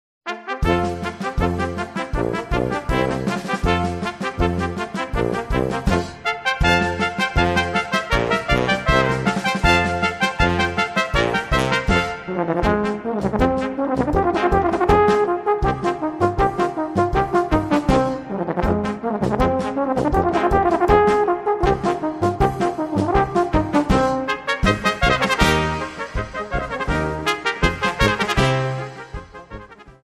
Gattung: Solo für Tenorhorn und Kleine Besetzung
Besetzung: Kleine Blasmusik-Besetzung
als Solostück für Tenorhorn